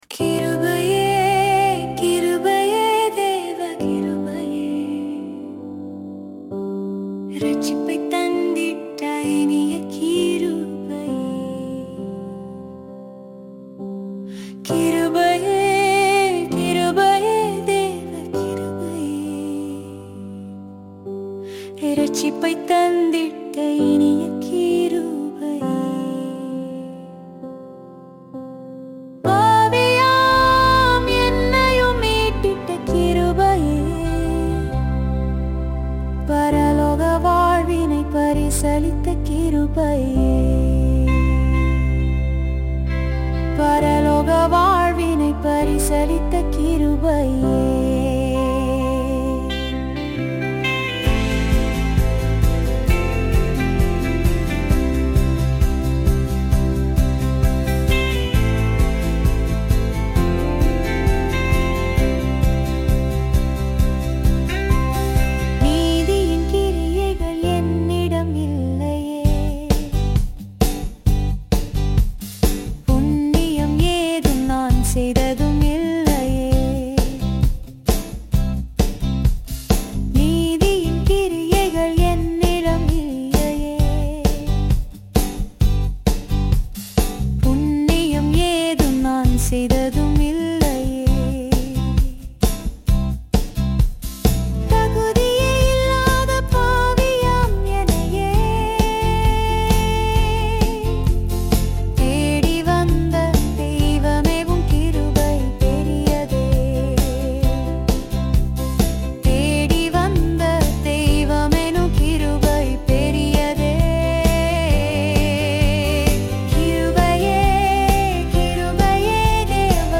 Royalty-free Christian music available for free download.